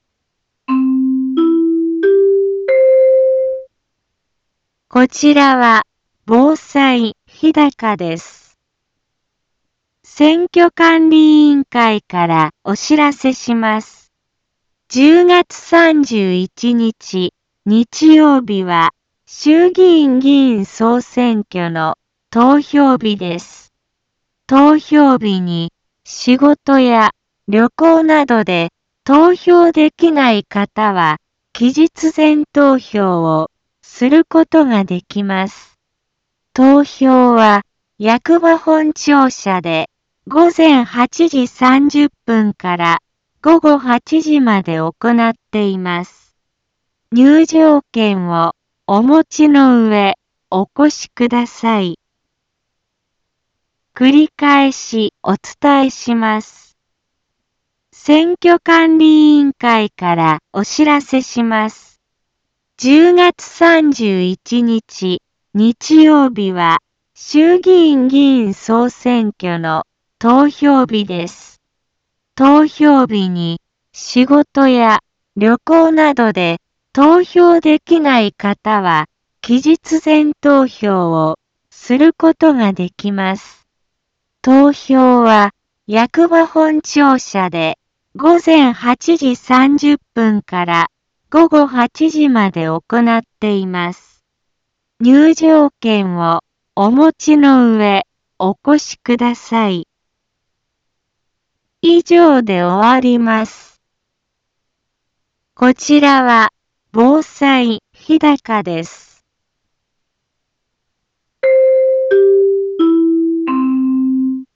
Back Home 一般放送情報 音声放送 再生 一般放送情報 登録日時：2021-10-20 15:04:03 タイトル：衆議院議員総選挙のお知らせ インフォメーション：こちらは防災日高です。